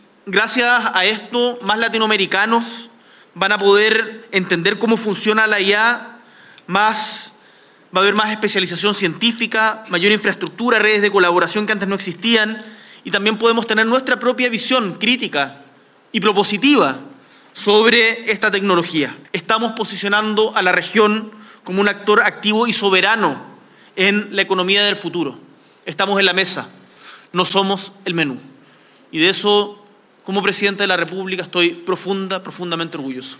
El Presidente Gabriel Boric encabezó este martes el lanzamiento de Latam-GPT, el primer modelo de lenguaje de inteligencia artificial de carácter abierto desarrollado íntegramente en América Latina y el Caribe, iniciativa que busca fortalecer la soberanía tecnológica de la región frente al dominio de las grandes potencias en esta materia.
CUNA-PRESIDENTE-BORIC.mp3